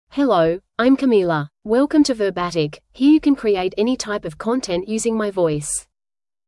FemaleEnglish (Australia)
CamilaFemale English AI voice
Camila is a female AI voice for English (Australia).
Voice sample
Female